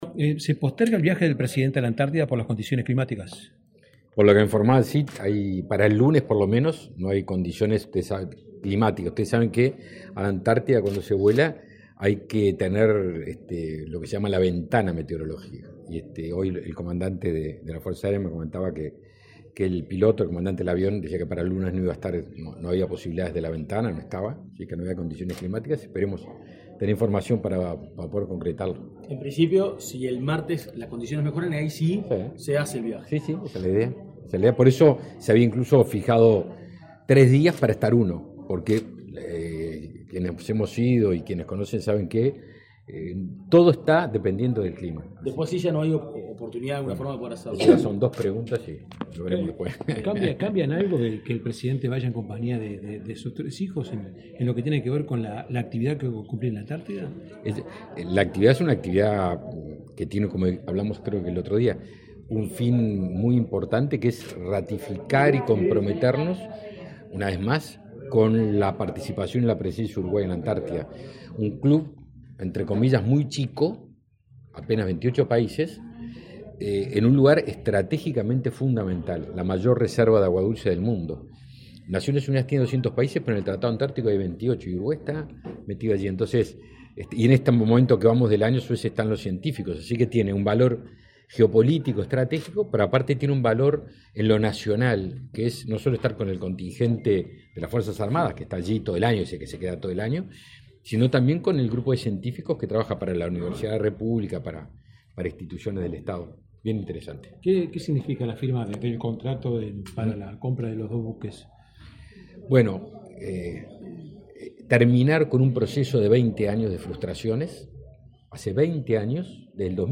Declaraciones del ministro de Defensa Nacional, Javier García
Declaraciones del ministro de Defensa Nacional, Javier García 15/12/2023 Compartir Facebook X Copiar enlace WhatsApp LinkedIn El ministro de Defensa Nacional, Javier García, dialogó con la prensa, luego de participar en el Club de Golf de Montevideo, de un almuerzo de la Cámara Española de Comercio.